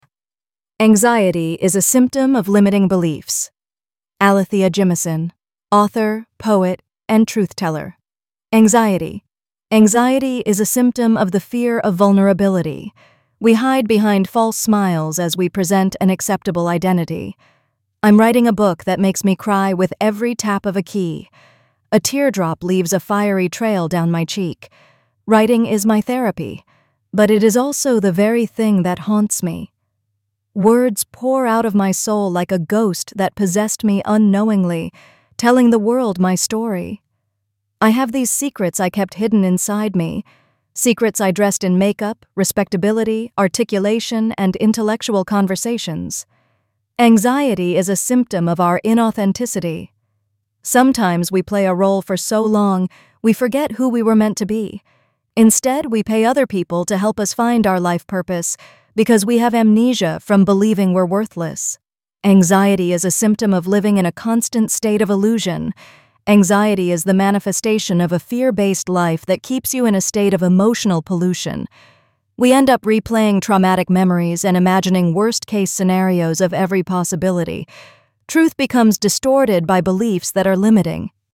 text-to-speech-tts